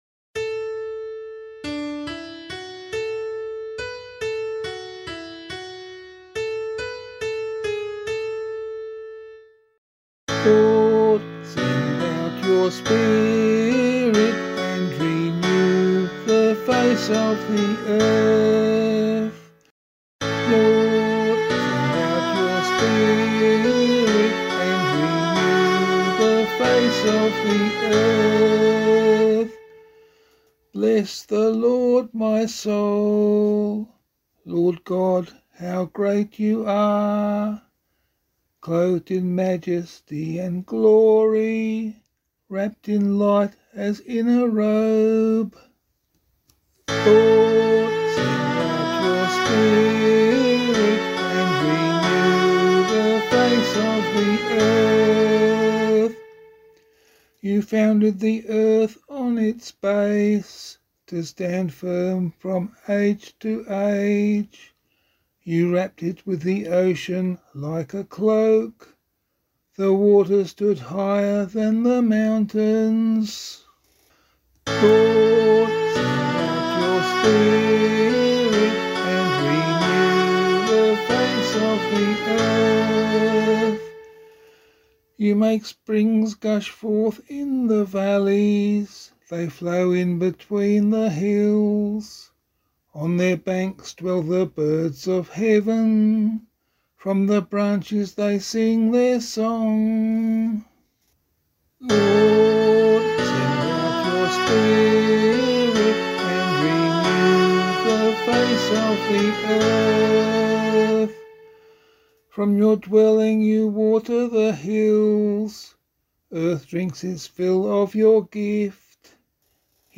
022 Easter Vigil Psalm 1A [LiturgyShare 8 - Oz] - vocal.mp3